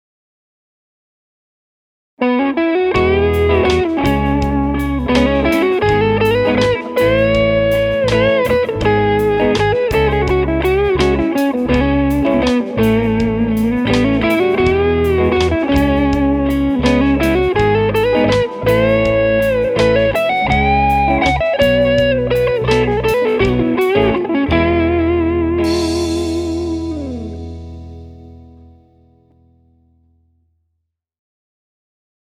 I was just noodling when I played a phrase that just begged to be laid down. So I figured out the chord progression that would go with the phrase, and here’s what I came up with:
Goldie has a bright, big voice, and true to all Saint guitars that I’ve had the priviledge to play, she has an even, dynamic range in all strings, up and down the fretboard.
🙂 This amp in particular is very pedal friendly, and I used a Tube Screamer to give me some grit on the lead, plus my new Hardwire Reverb.
roxbox22_6v6_saint_goldtop.mp3